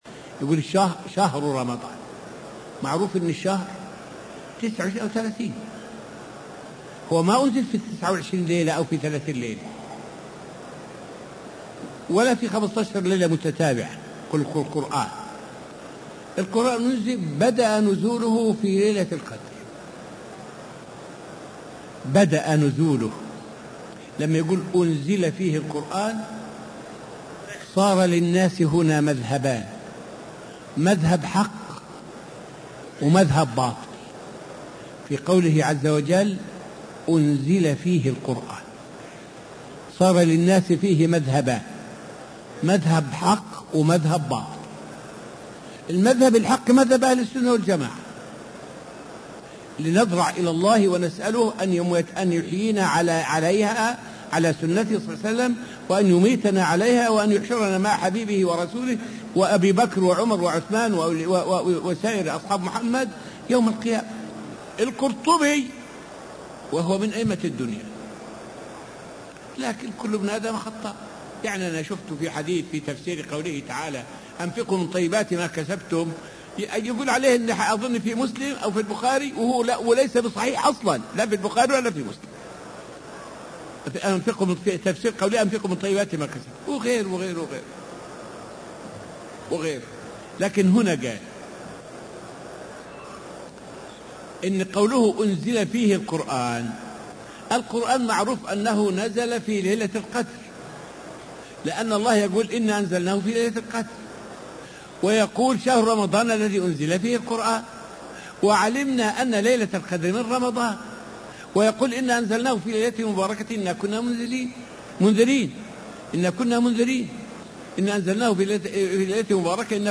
الدرس السادس من دروس تفسير سورة القمر والتي ألقاها الشيخ في رحاب المسجد النبوي حول من قوله تعالى {ولقد يسرنا القرآن للذكر فهل من مدكر} الآية 22 إلى قوله تعالى {ولقد يسرنا القرآن للذكر فهل من مدكر} الآية 32.